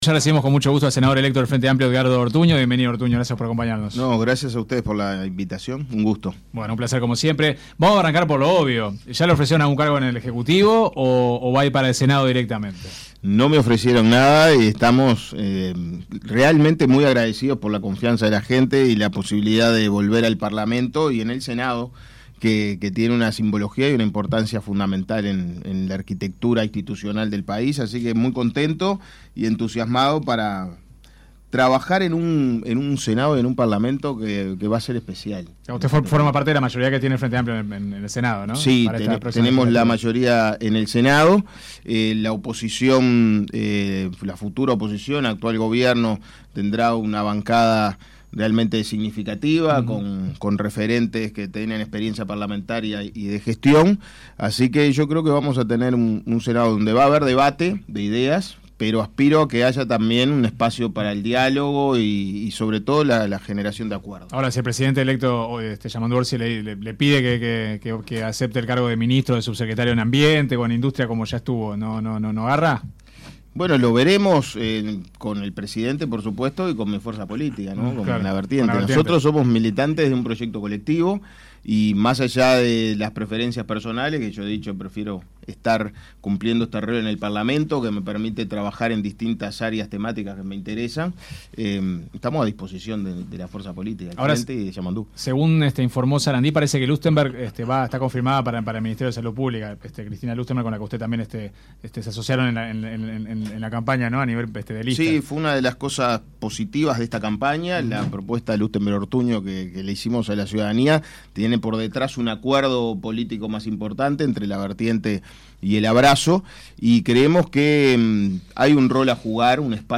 El senador electo por el Frente Amplio, Edgardo Ortuño dijo en entrevista con 970 Noticias, que la coalición de izquierdas en sus Bases Programáticas plantea construir la represa en Casupá y no el Proyecto Neptuno en Arazatí por todos los cuestionamientos políticos, sociales, académicos y ambientales que se han realizado.